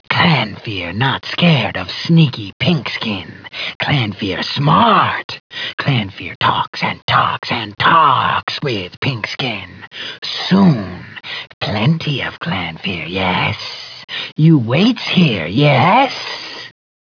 Voice line of Reply 1 of Truculent dialogue from a Clannfear in Battlespire.
BS-Clannfear_Talks.wav